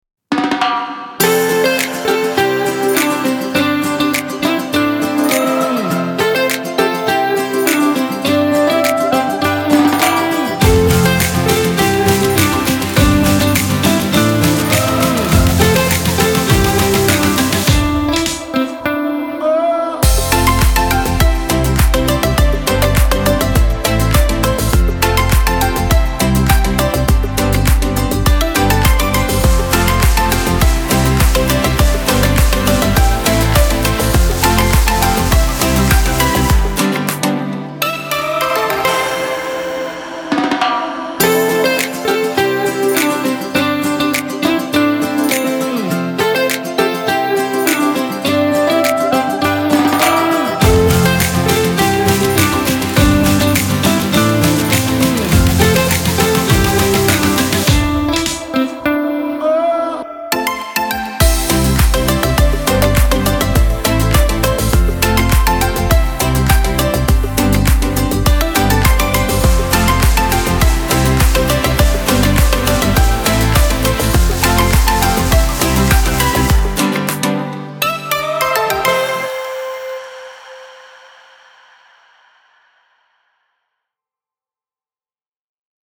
is a gentle chill track
Ringtone